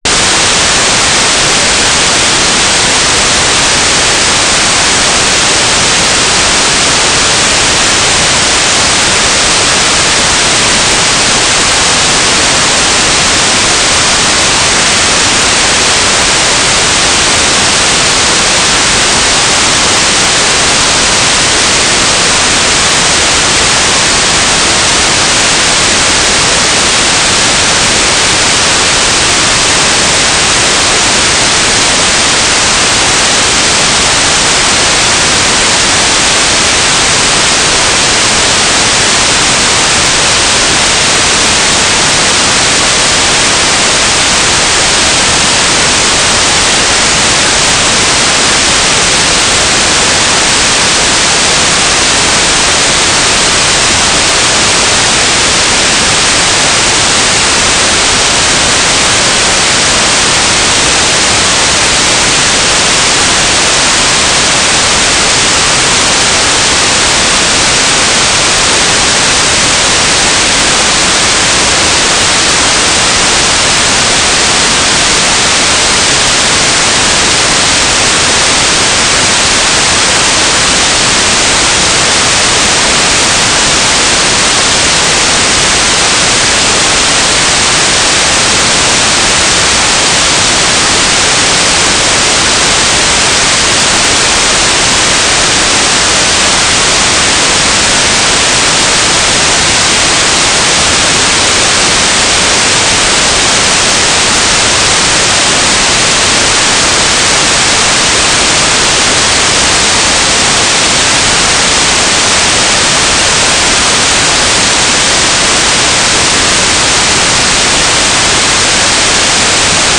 "station_name": "UHF-VHF Terrace station",
"transmitter_description": "9k6 FSK TLM",